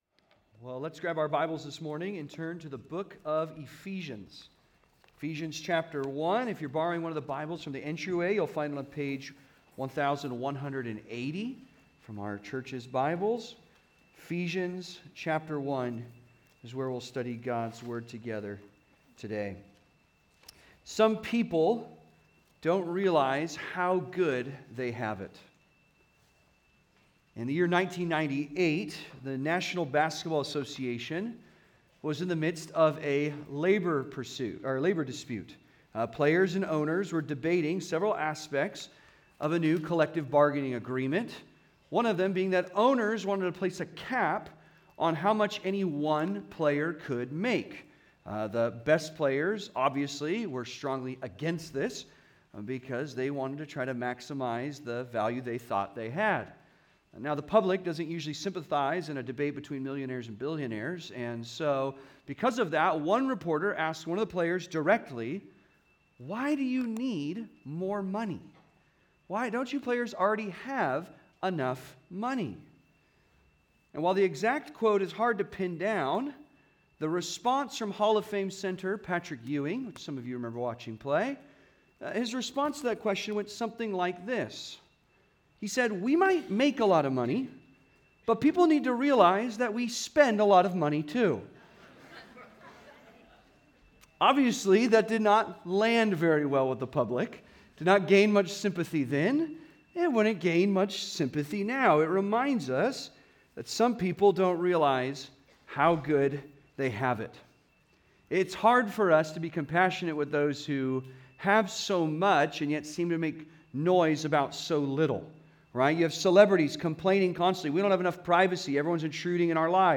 Redeemed by the Son (Sermon) - Compass Bible Church Long Beach